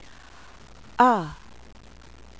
kametz Sephardic "a" as in father
(Ashkenazic "aw" as in saw)